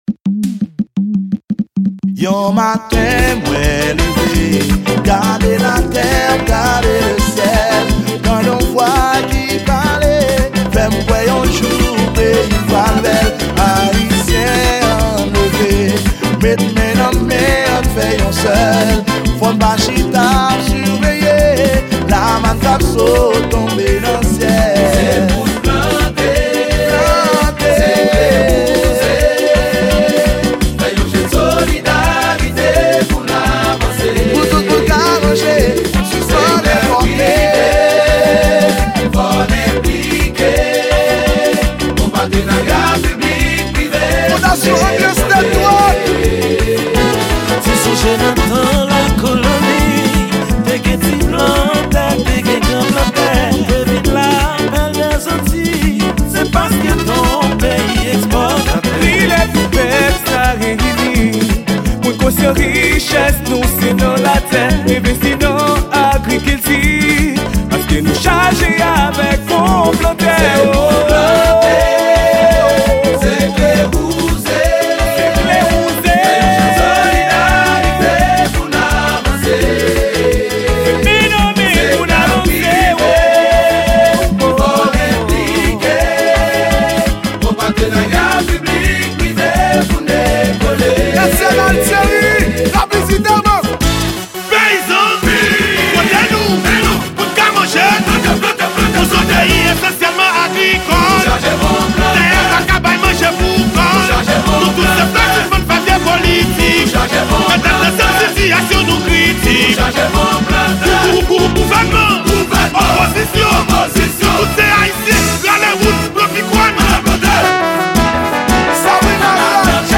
Genre: KANAVAL 2016.